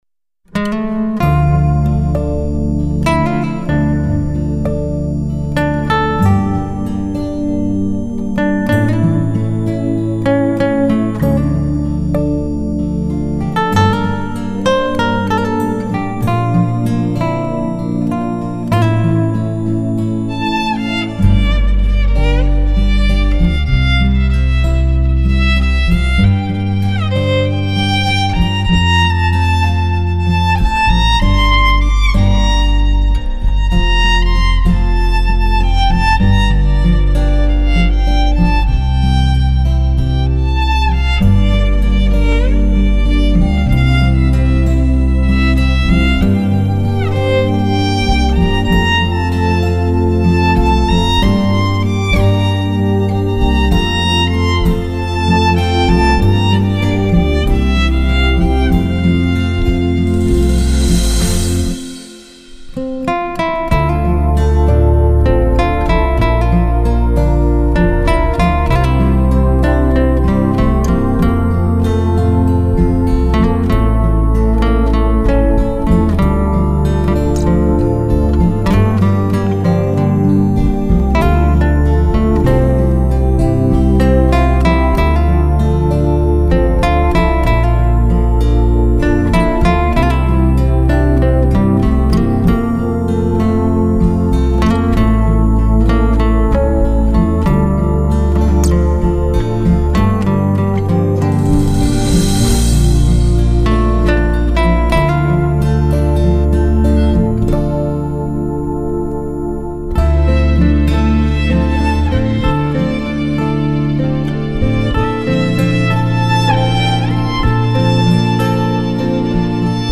浪漫新世纪音乐
大提琴
小提琴
双簧管
吉他
贝斯